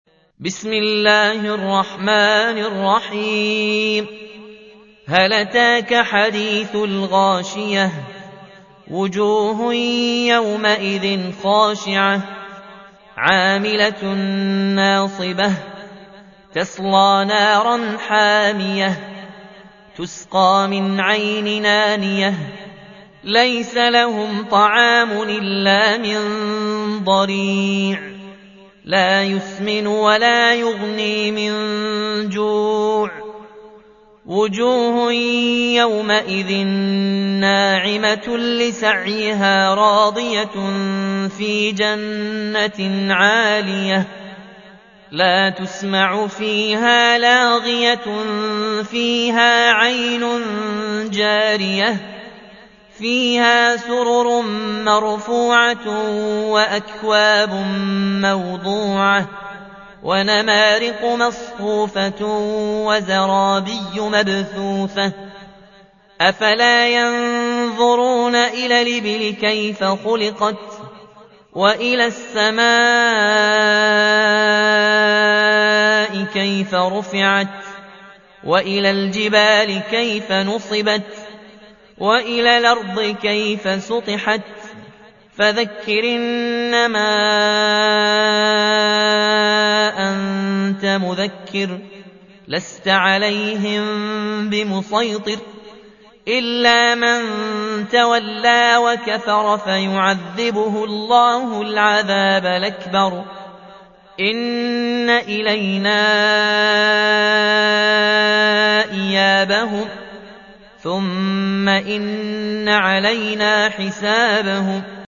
88. سورة الغاشية / القارئ